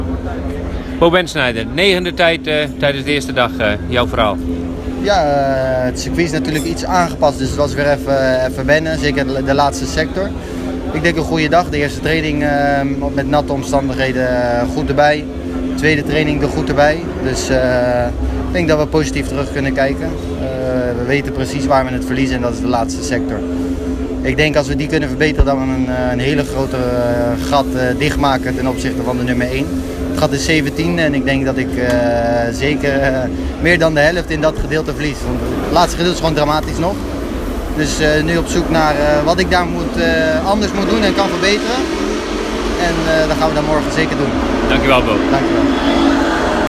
Direct na afloop vroegen we Bo Bendsneyder om een reactie naar zijn eerste dag. In onderstaand audio verslag het verhaal van de Nederlander: